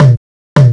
PERC LOOP7-R.wav